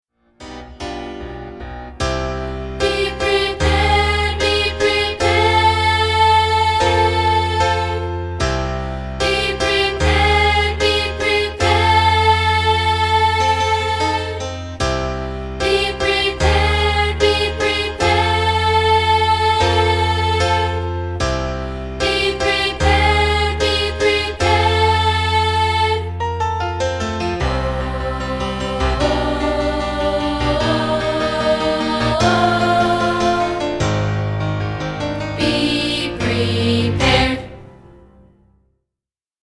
This are the optional divisi lines, isolated.